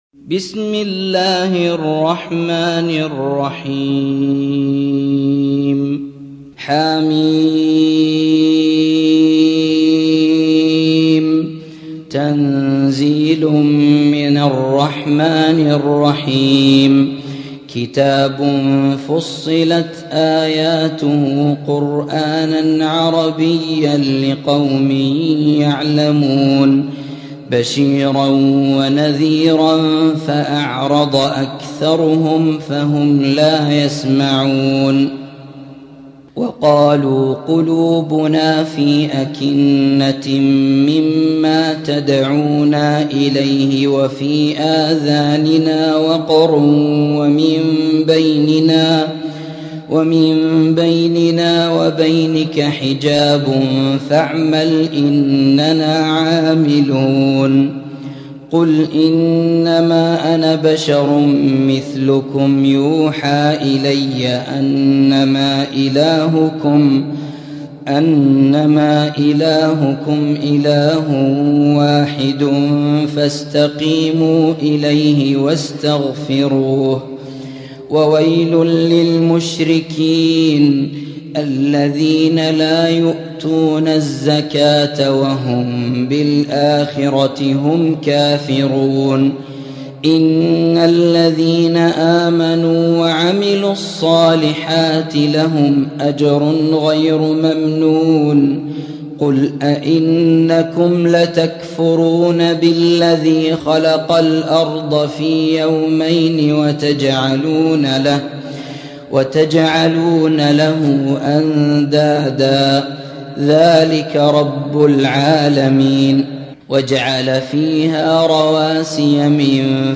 41. سورة فصلت / القارئ